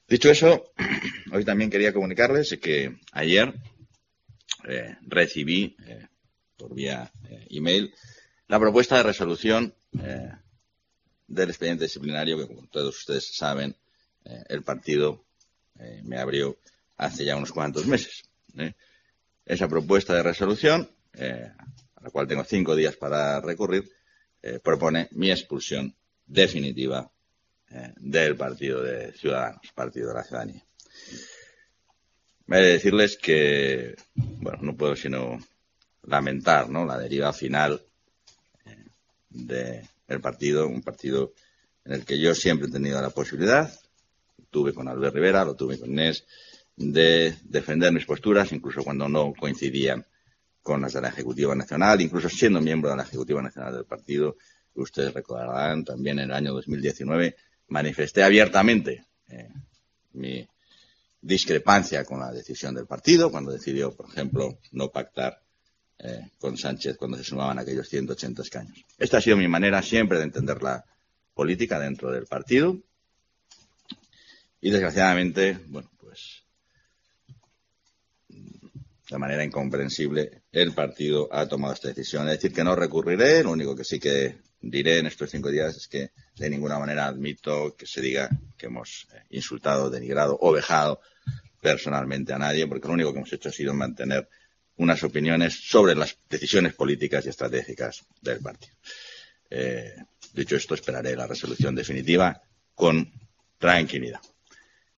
Ha sido el propio exvicepresidente y exportavoz de la Junta y hasta ahora único procurador de la formación naranja en las Cortes de Castilla y León quien ha anunciado en la rueda de prensa posterior a la reunión de la Junta de Portavoces del Parlamento autonómico la propuesta de resolución del expediente que le abrió Cs hace meses.